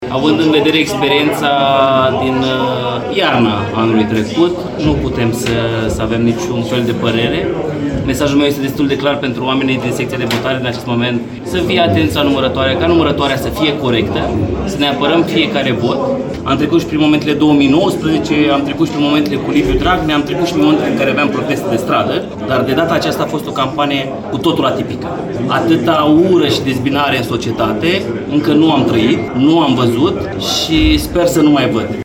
AUDIO / Declarații după primul tur al prezidențialelor: bucurie la AUR Suceava, speranțe în rest